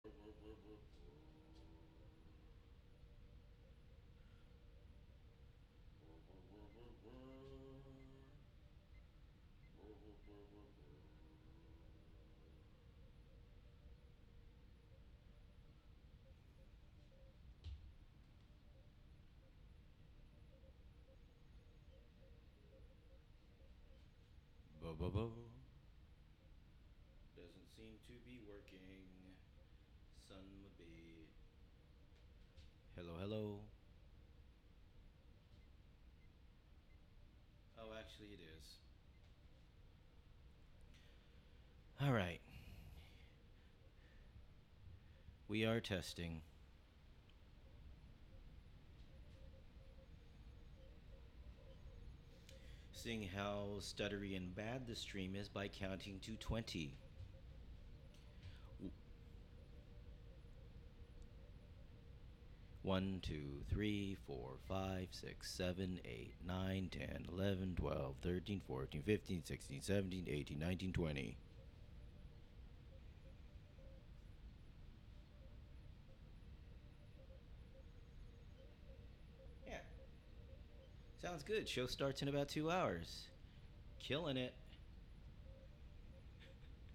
Live from the Thingularity Studios: Thingularity (Audio) Jan 05, 2023 shows Live from the Thingularity Studios LIVE from The Rathskeller in Catskill Heights Play In New Tab (audio/mpeg) Download (audio/mpeg)